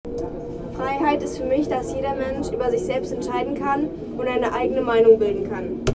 Standort der Erzählbox:
Stendal 89/90 @ Stendal